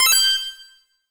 Menu_Navigation02_Open.wav